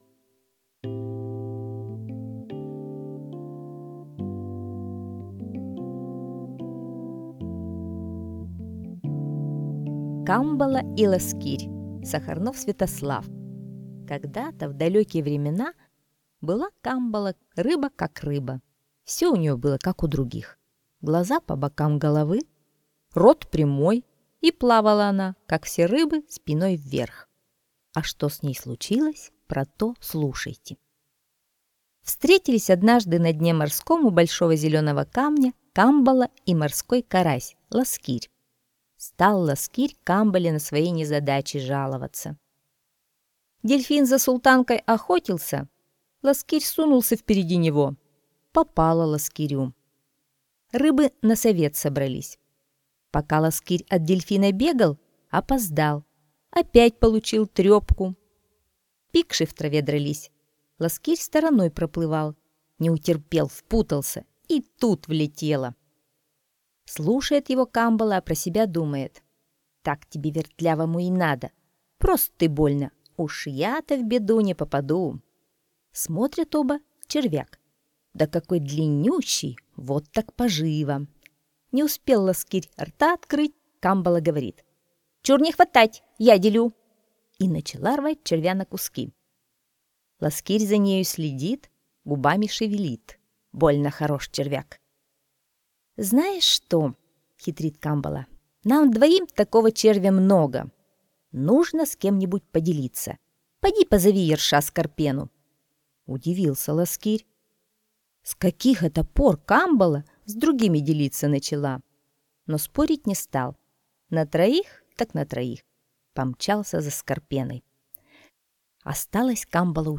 Камбала и ласкирь - аудиосказка Сахарнова С.В. Слушайте сказку «Камбала и ласкирь» онлайн на сайте Мишкины книжки.